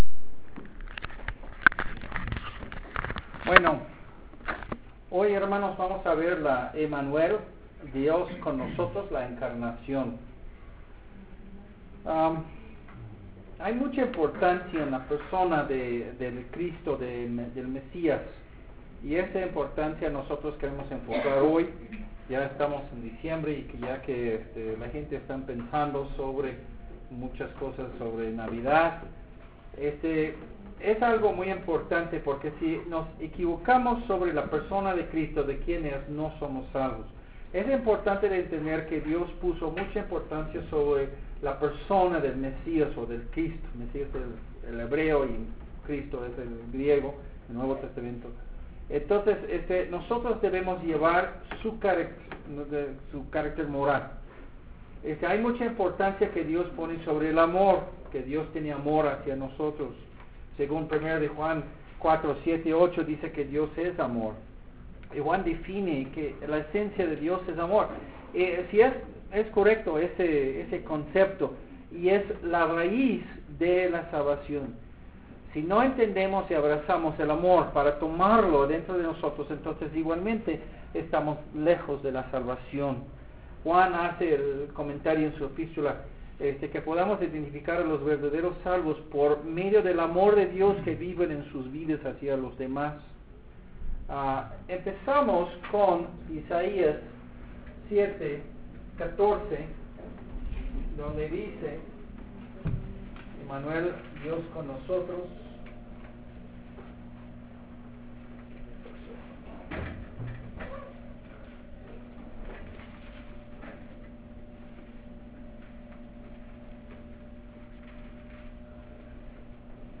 Un sermón de 1980 sobre la encarnación de Jesús como nuestro Salvador.